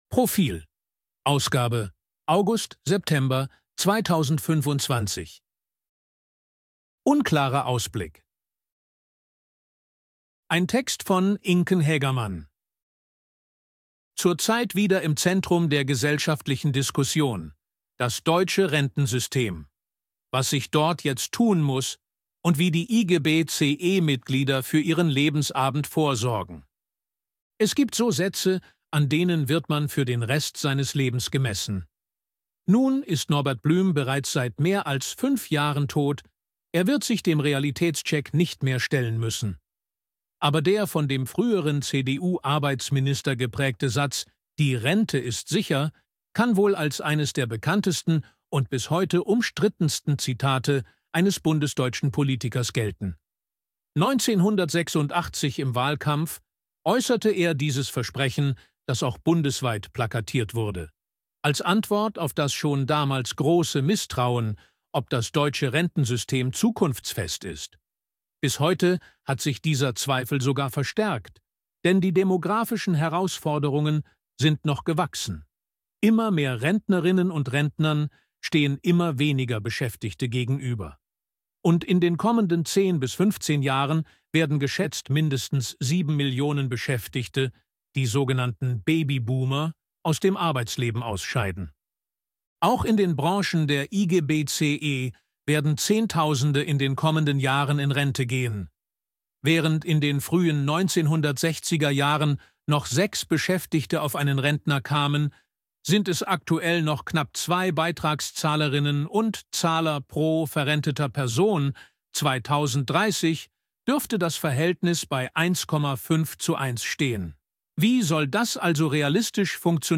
ElevenLabs_254_KI_Stimme_Mann_HG-Story.ogg